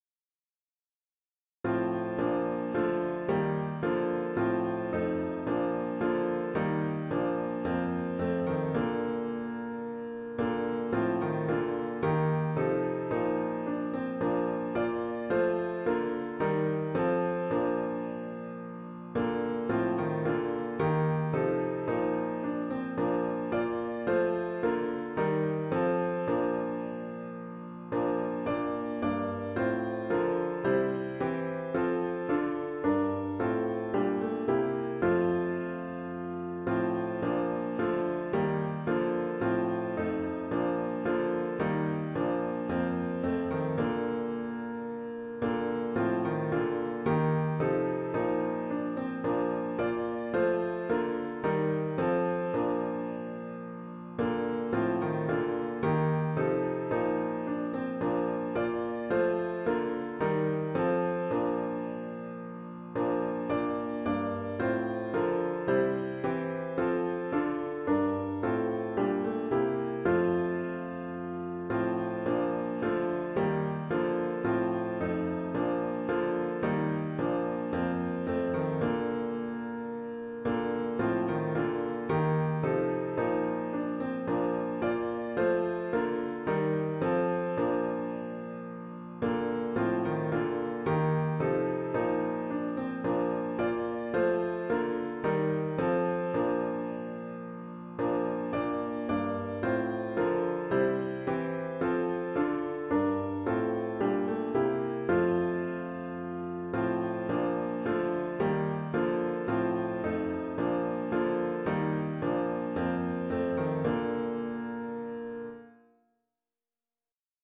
CLOSING HYMN “O Lord, How Shall I Meet You” GtG 104